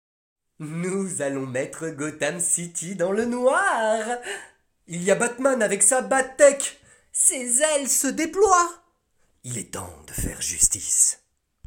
Voix off
30 - 40 ans - Ténor